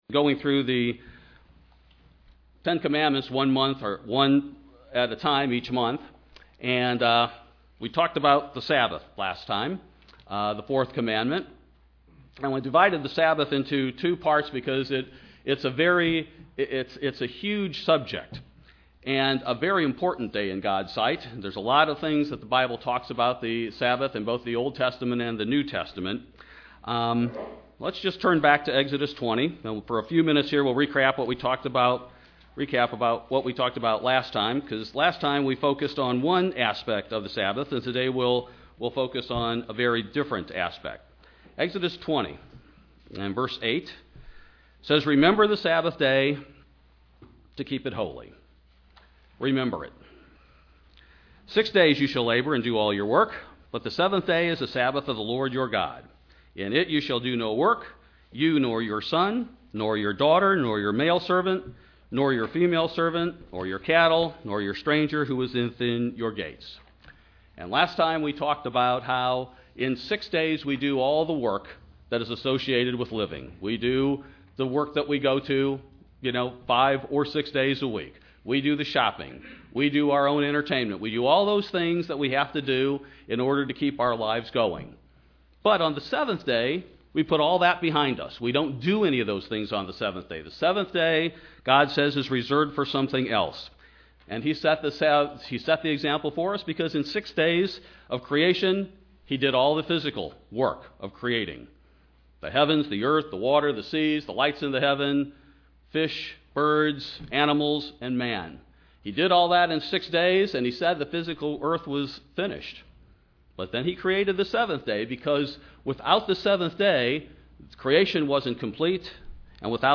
Part two of a two part sermon on the fourth commandment. What we should do on the Sabbath and what God's purpose for us is.